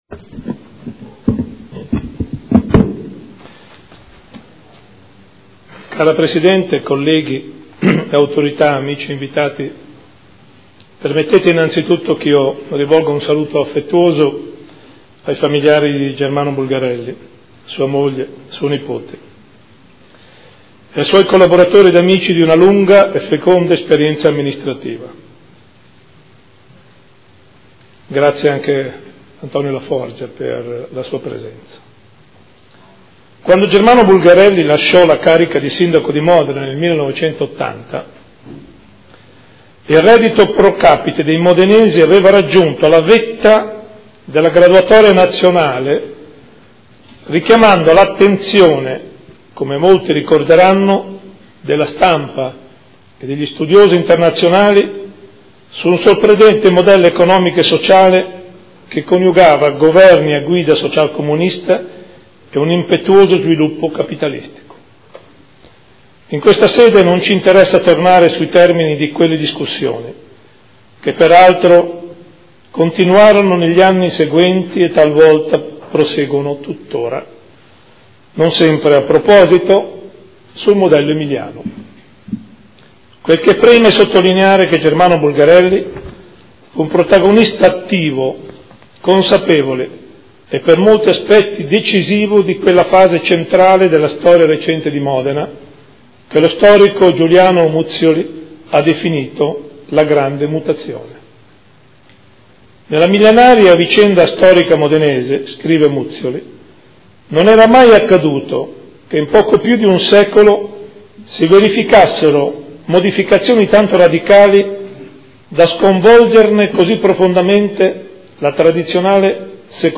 Seduta del 2 luglio. Commemorazione del Sindaco Germano Bulgarelli ad un anno dalla scomparsa. Introduzione del Sindaco di Modena – Gian Carlo Muzzarelli